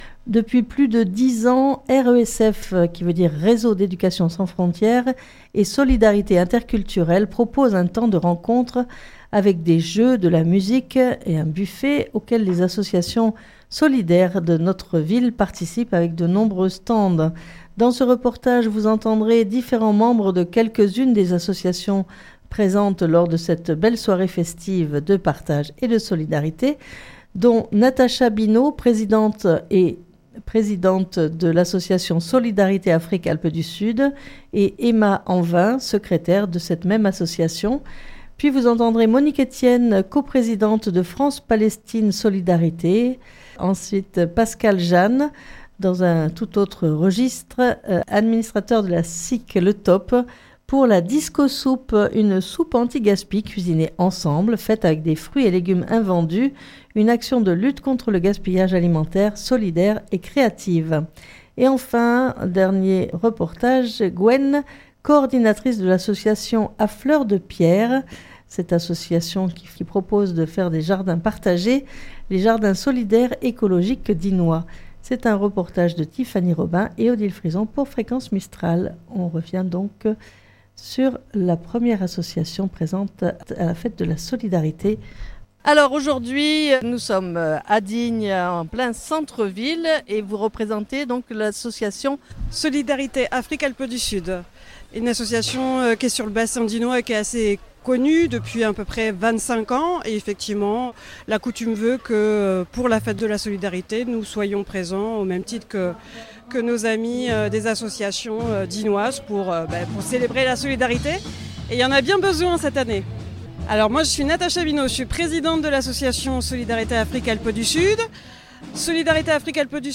Depuis plus de 10 ans RESF (Réseau d'Education Sans Frontière) et solidarités interculturelles proposent un temps de rencontre avec jeux, musique et buffet auquel les associations solidaires de notre ville participent avec de nombreux stands. Dans ce reportage, vous entendrez différents membres de quelques-unes des associations présentes lors de cette belle soirée festive de partage et de solidarité.